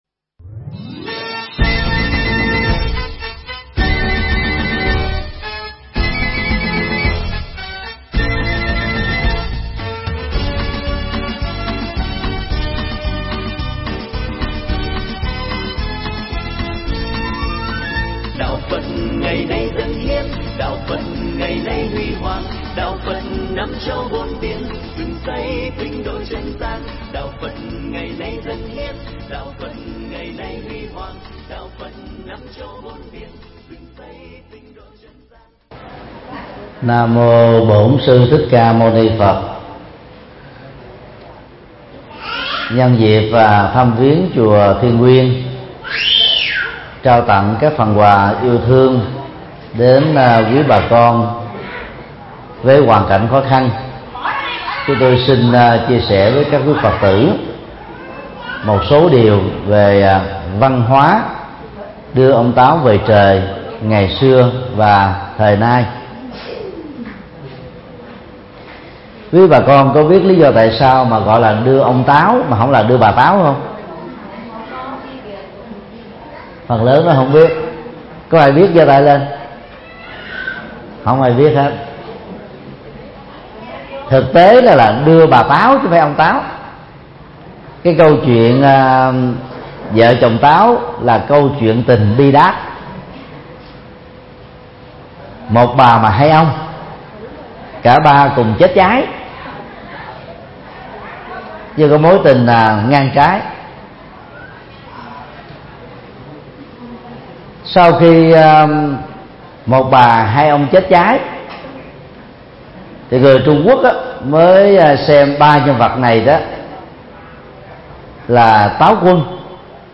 Mp3 Thuyết Pháp Văn hóa Táo quân xưa và nay
Giảng tại chùa Thiên Nguyên, thị xã Gò Công, tỉnh Tiền Giang